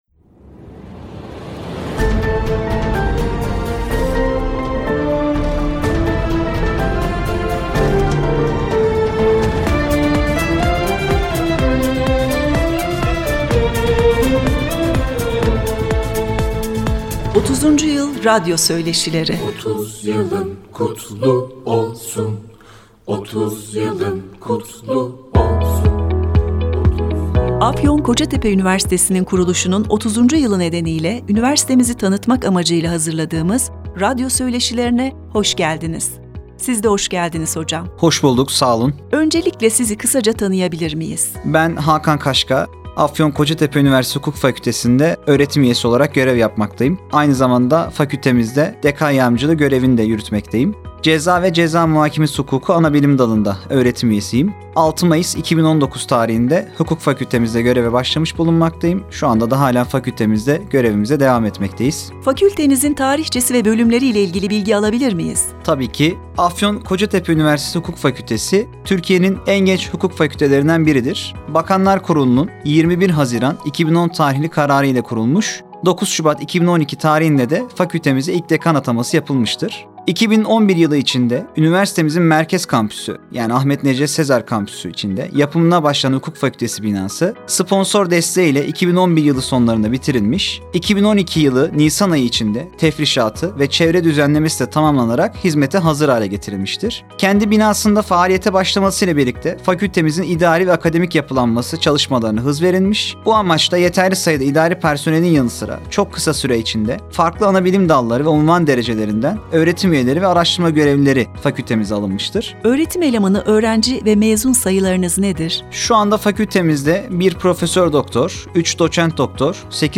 3 Ekim 2022 Pazartesi günü saat 14:00’te gerçekleştirdiğimiz yayını